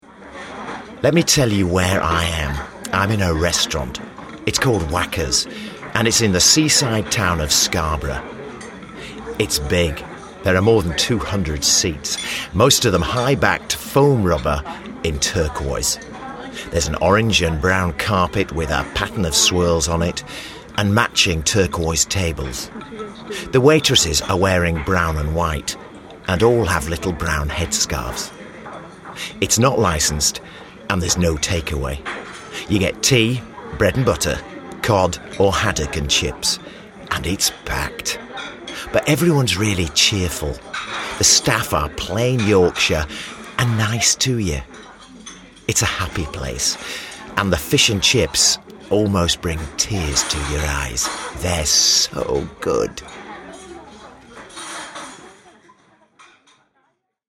Northern/Yorkshire
Natural Yorkshire male voice for Northern Restaurant
restaurant-northern-vo.mp3